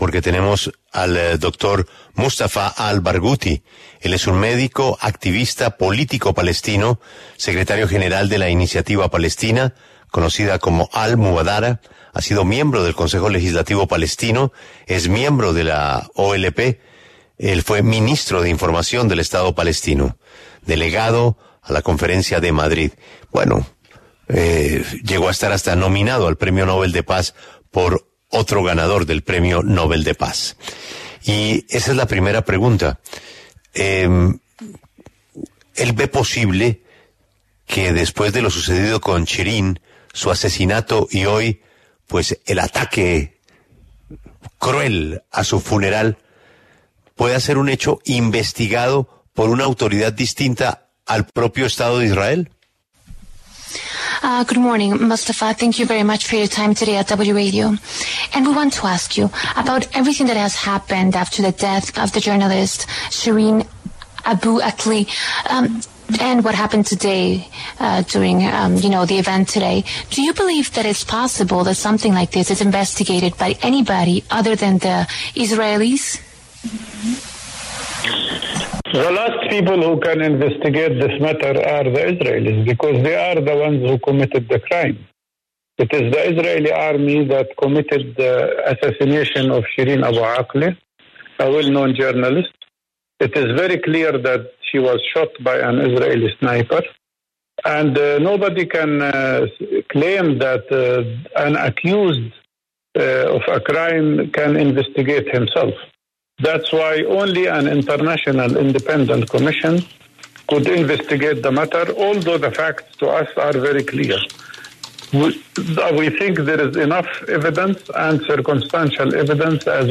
El médico y activista político Mustafa Al Barghouti habló en La W sobre las investigaciones que se adelantan sobre el asesinato de la periodista Shireen Abu Akleh.